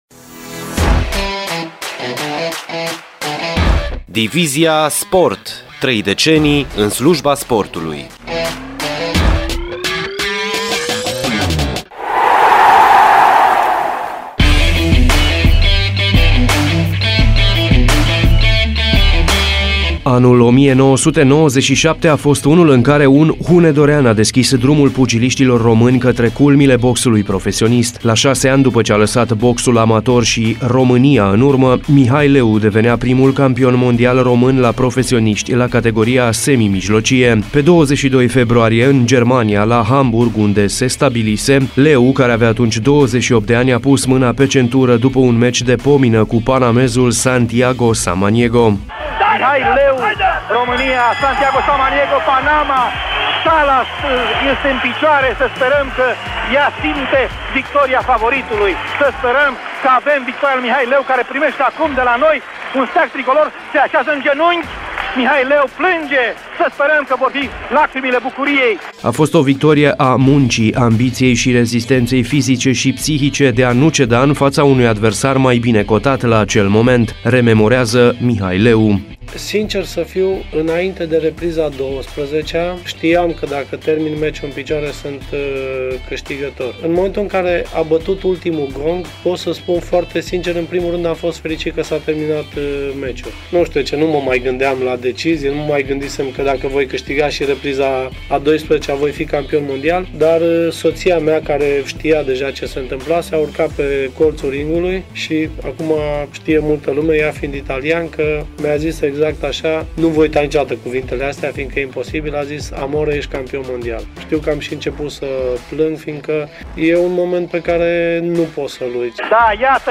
Rememorează, Mihai Leu, într-un reportaj